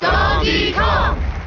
File:Donkey Kong Cheer JP Melee.ogg
Donkey_Kong_Cheer_JP_Melee.ogg.mp3